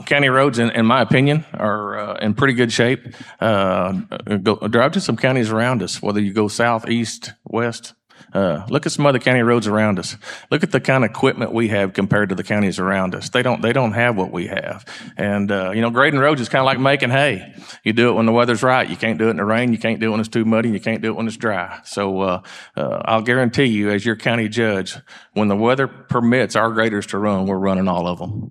The public forum held at the Food Bank of North Central Arkansas saw County Judge Kevin Litty face challenger Eric Payne who currently serves as Justice of the Peace for District 11.